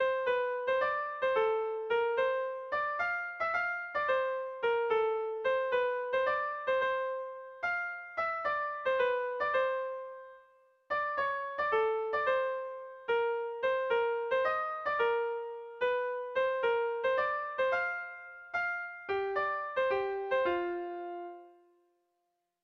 Bertso melodies - View details   To know more about this section
Sentimenduzkoa
ABDE